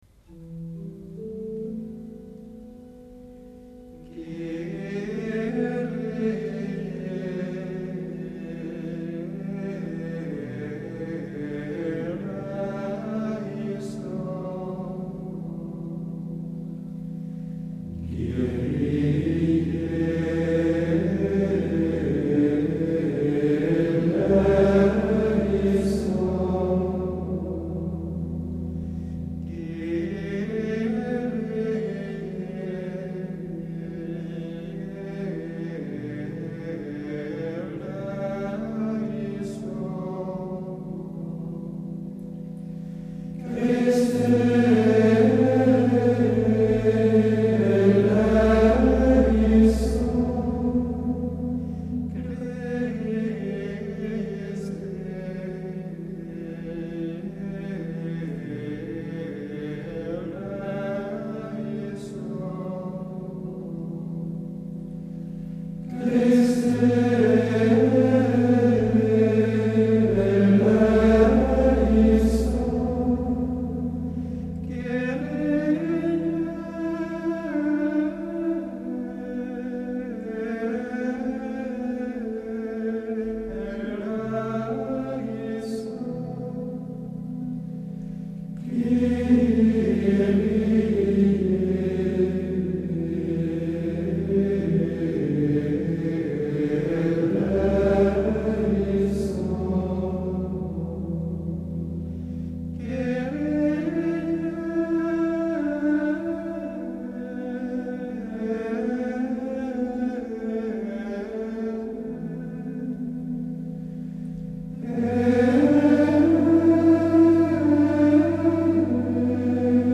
Voilà un beau Kyrie du 1er mode, daté du XIIe siècle. Il serait une reprise française du Kyrie 10 qui, lui, serait d’origine catalane, au XIe siècle.
La mélodie part du Ré, fondamentale du 1er mode, puis monte jusqu’au La, dominante de ce même mode, en faisant entendre le Fa et le Sol.
On a donc clairement deux élans et deux retombées avec au milieu une petite ritournelle très chantante.
Ce second Kyrie est plus piano, plus doux.
Il doit être chanté un peu plus fort.
Tout est serein, frais, enthousiaste aussi, mais d’une joie mesurée, calme, virginale.